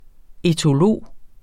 Udtale [ etoˈloˀ ]